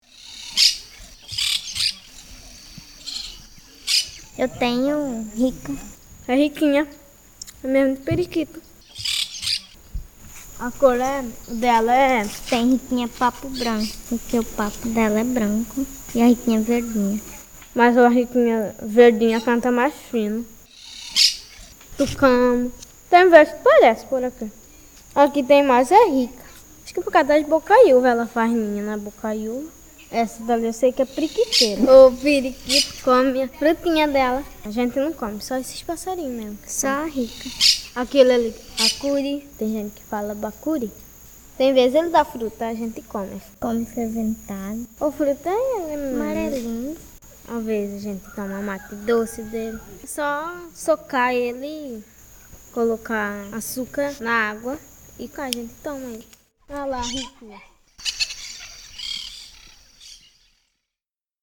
Conversa sobre pescaria e as riquinhas